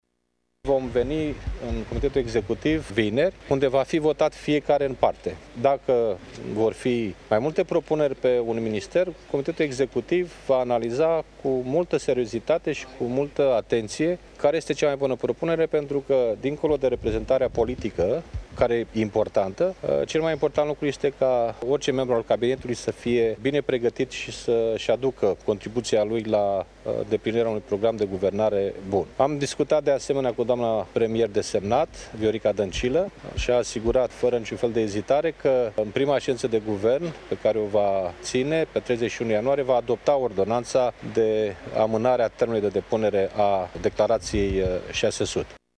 Președintele social democrat a precizat că fiecare ministru va fi votat in parte: